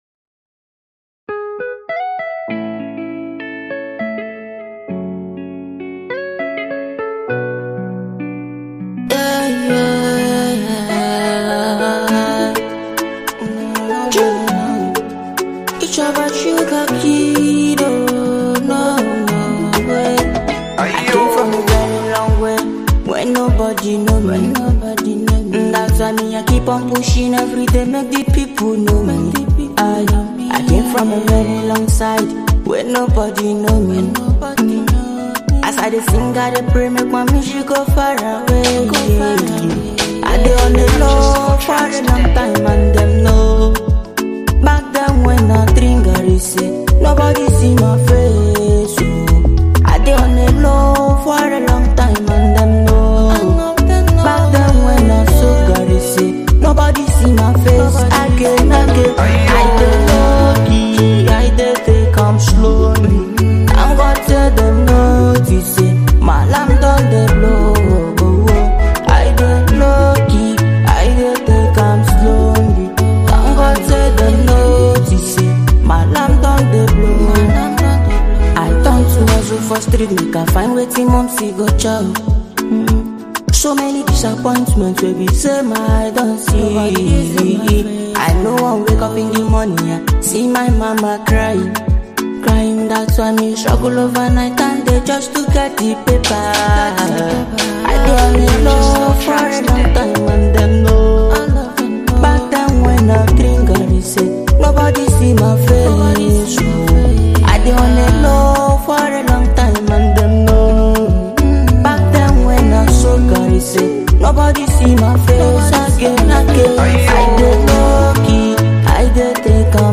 Afro trending song